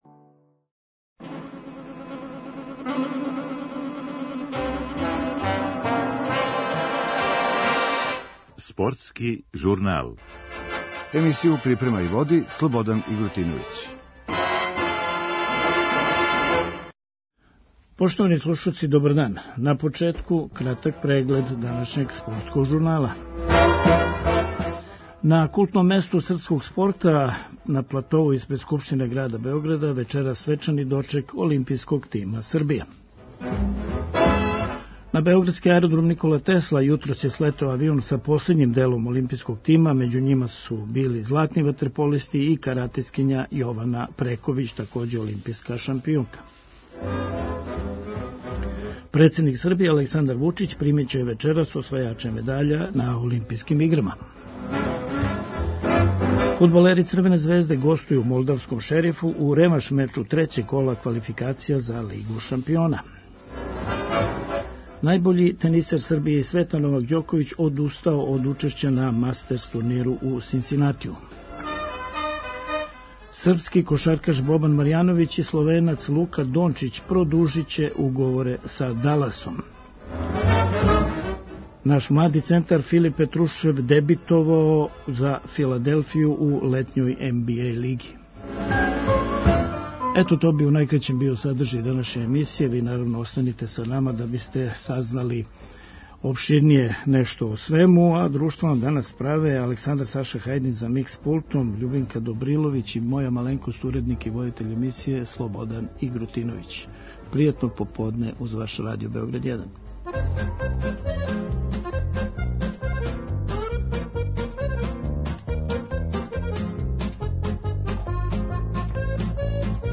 Чућемо прве изјаве по доласку.